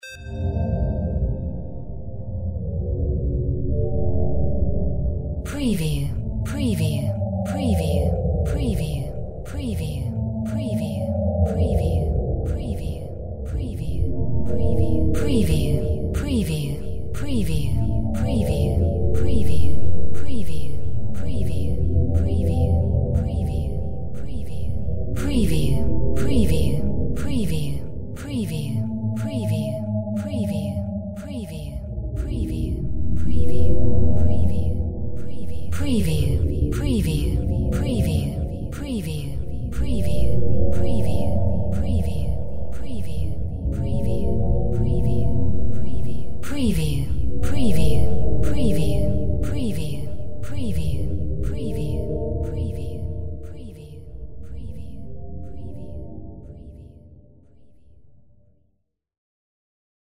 Drone Whale Pulse Hi 01
Stereo sound effect - Wav.16 bit/44.1 KHz and Mp3 128 Kbps
previewDRONE_HI_WHALE_PULSEFX_WBSD01.mp3